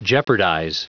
Prononciation du mot jeopardize en anglais (fichier audio)
Prononciation du mot : jeopardize